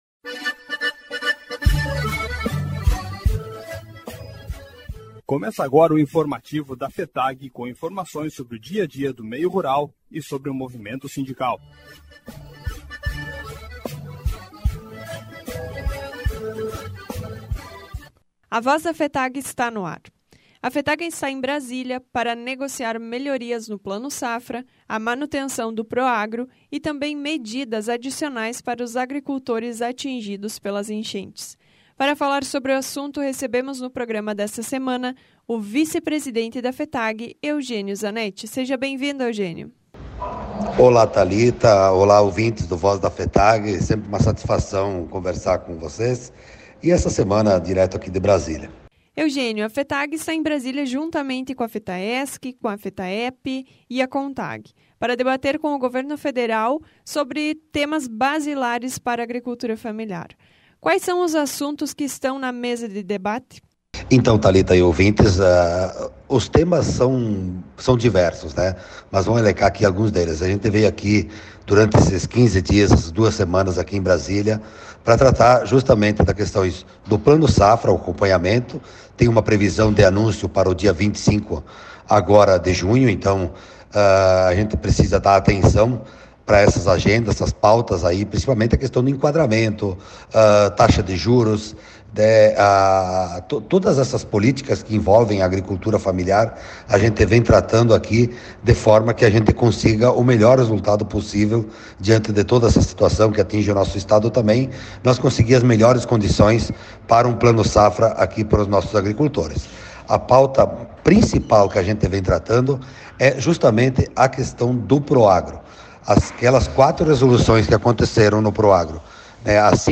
Programa de Rádio A Voz da FETAG-RS